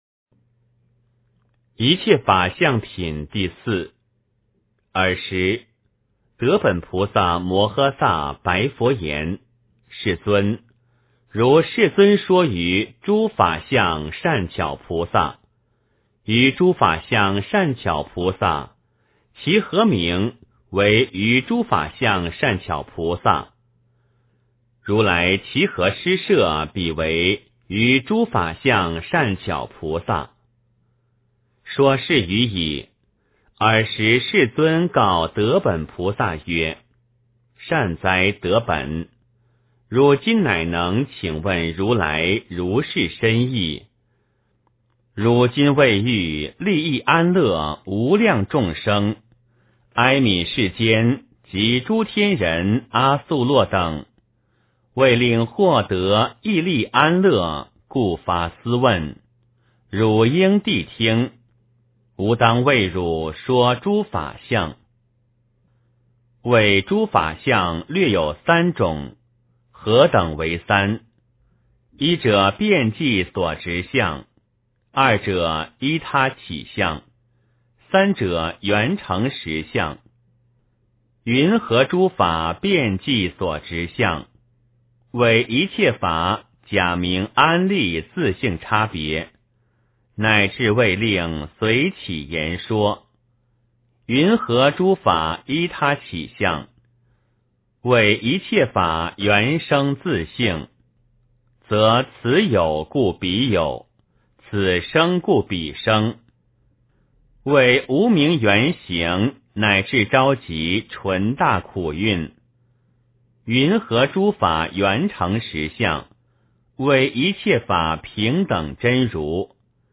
解深密经-4（念诵）